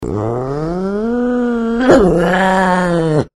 Злая пантера